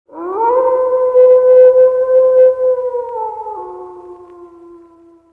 Wolf Howlin'
wolf-howlin.mp3